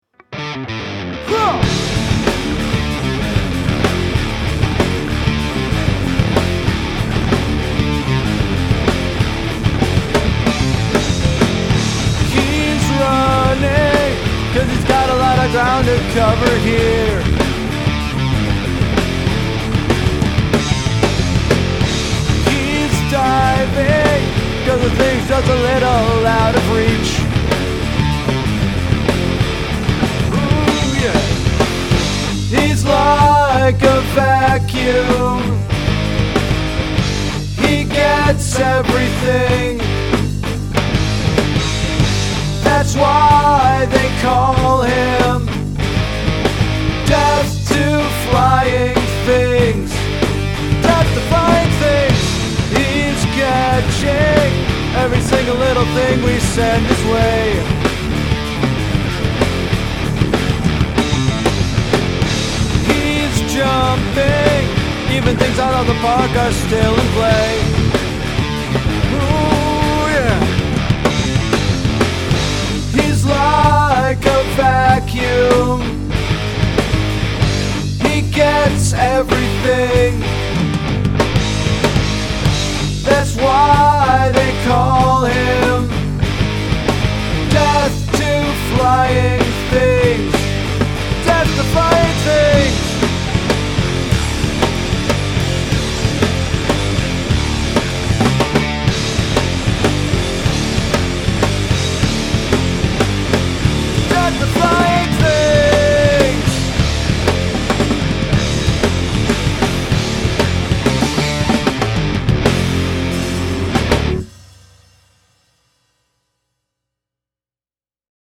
I went after the latter two here (Pearl Jam sits the best in my voice range, but the end of the song is pure Soundgarden – the closing riff is in 5 and the next to last measure is in 7).